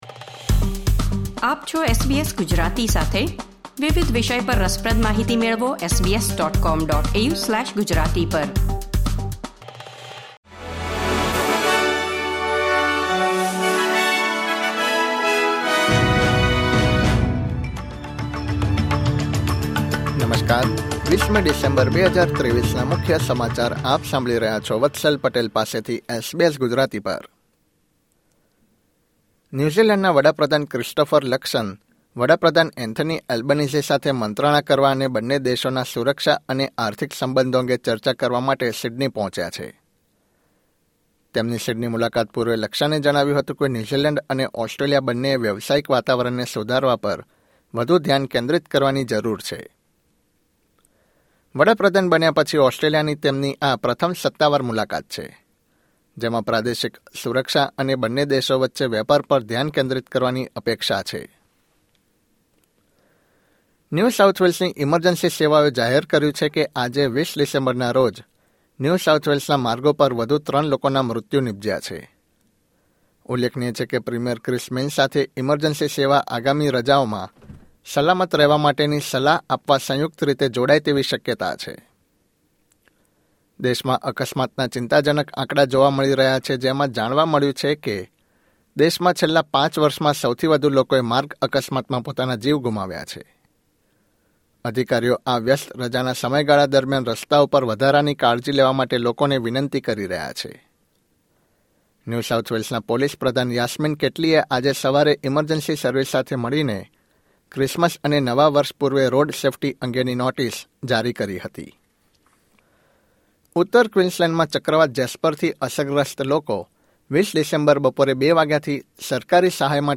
SBS Gujarati News Bulletin 20 December 2023